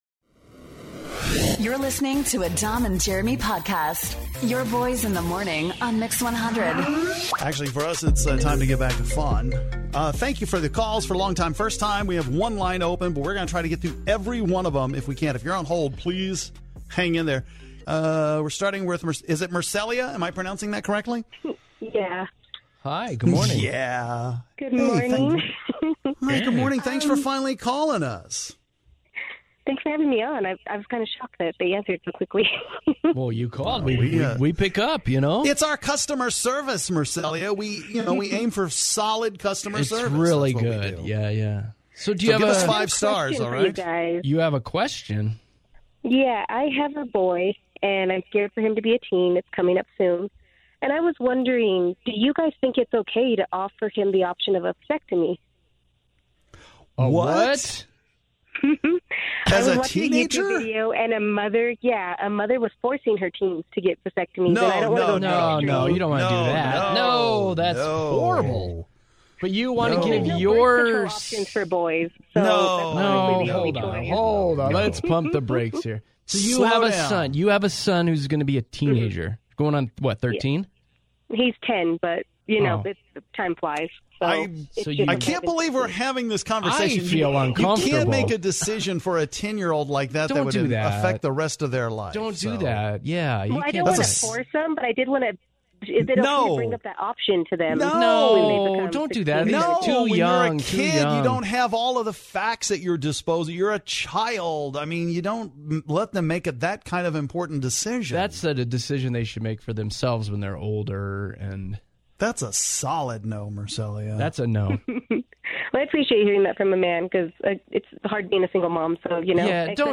We talk to our long time listeners here who have never called in before.